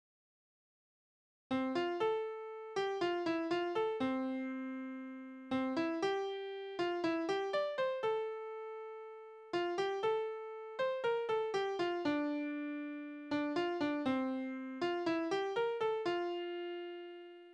Balladen: Er findet seine Liebste als Gärtnersfrau wieder
Tonart: F-Dur
Taktart: 4/4
Tonumfang: große None
Besetzung: vokal